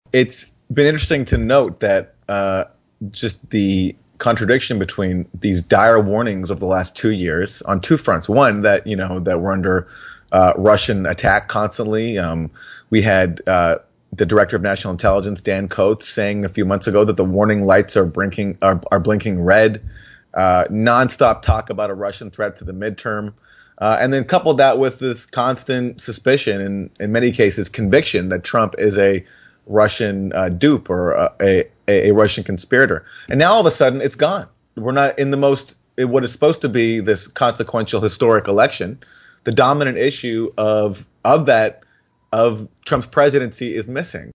In-Depth Interview: Journalist Aaron Maté Says “Russiagate” Is MIA From Midterm Campaigns